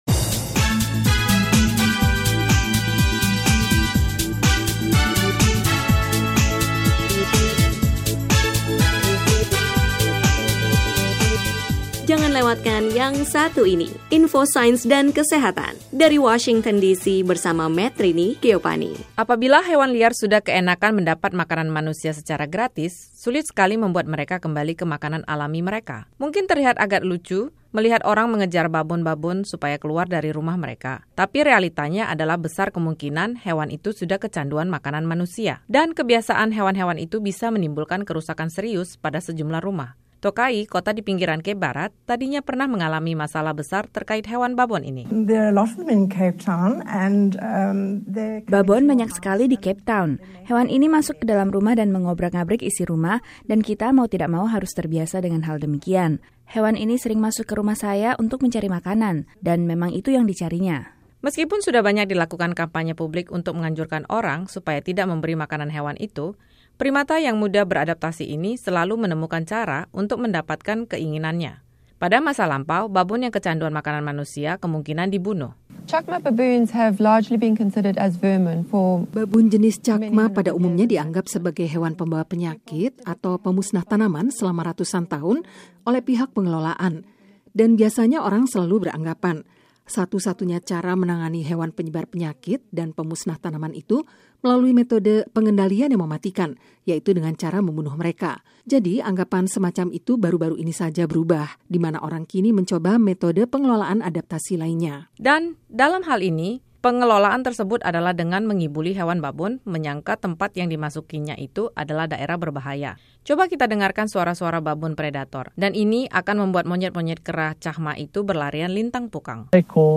Tersedia beragam makanan gratis yang sering kita buang di sekitar rumah menjadi masalah hewan Babon di salah satu satu daerah Afrika Selatan, sampai saat ini. Laporan